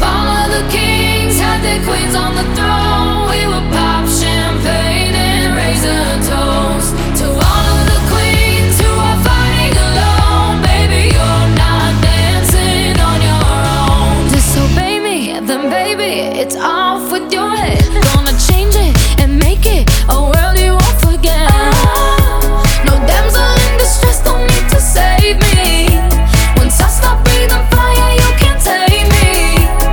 • Pop
power pop song